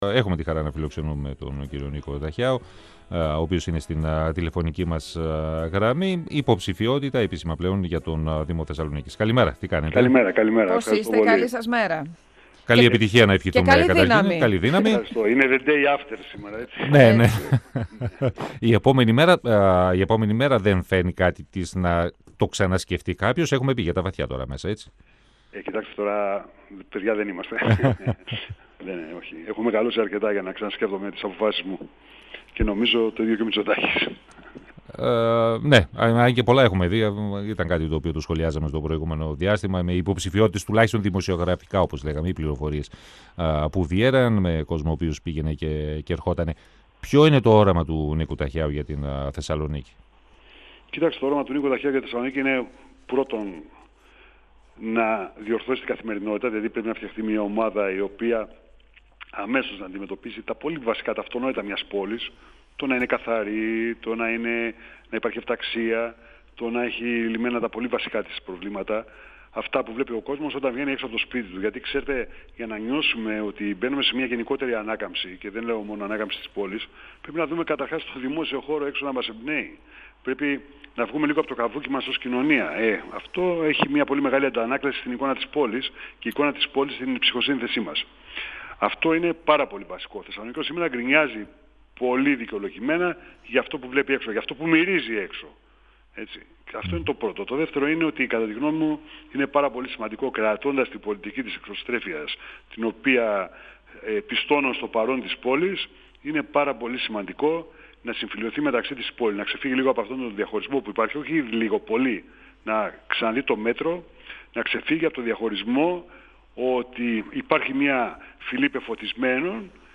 Την υποψηφιότητά του για τη δημαρχία της Θεσσαλονίκης επισημοποίησε ο Νίκος Ταχιάος και μιλώντας στον 102FM της ΕΡΤ3 αναφέρθηκε στις προτεραιότητες που θέτει για την προσπάθεια που αναλαμβάνει. Η ενίσχυση της εξωστρέφειας είναι αναγκαία, ανέφερε ο κ. Ταχιάος και πίστωσε τις θετικές εξελίξεις στο συγκεκριμένο θέμα στην παρούσα δημοτική Αρχή, αλλά πρόσθεσε ότι επείγουν η αντιμετώπιση ζητημάτων όπως το κυκλοφοριακό και η καθαριότητα στην πόλη.
Συνεντεύξεις